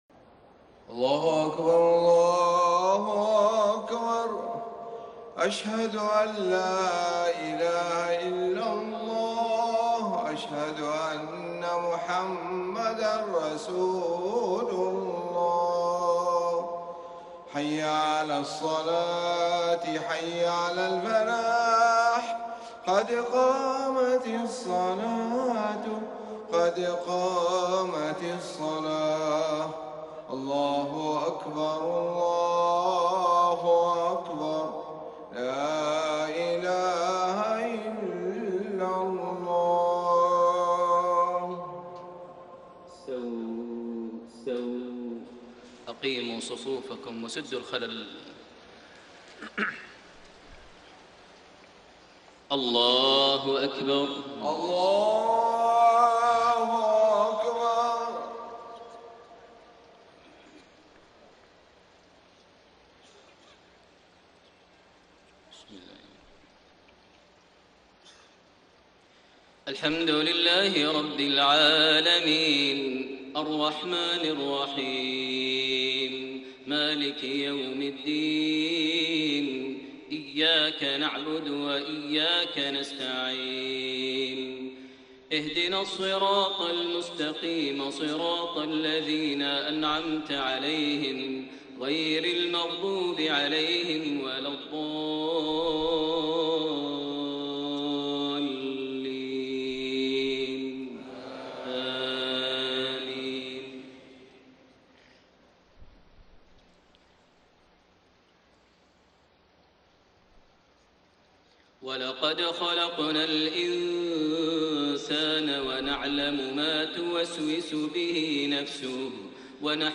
صلاة المغرب 8 شعبان 1433هـ من سورة ق 16-35 > 1433 هـ > الفروض - تلاوات ماهر المعيقلي